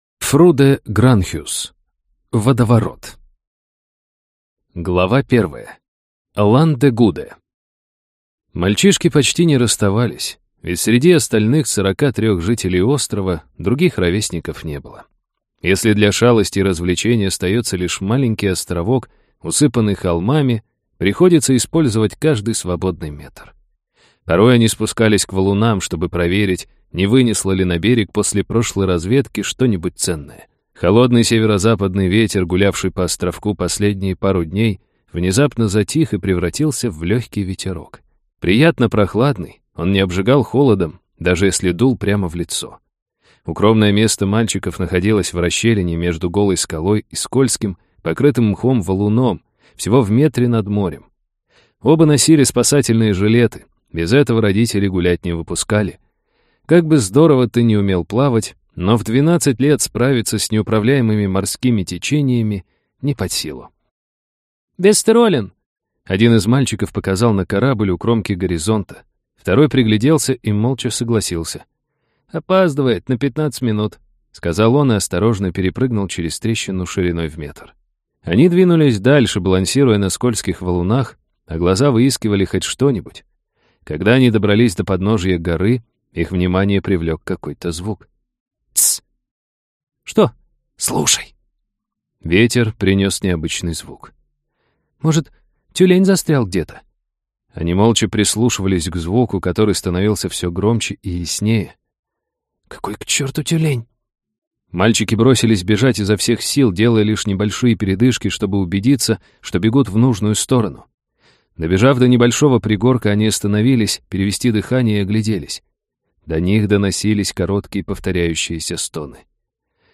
Аудиокнига Водоворот | Библиотека аудиокниг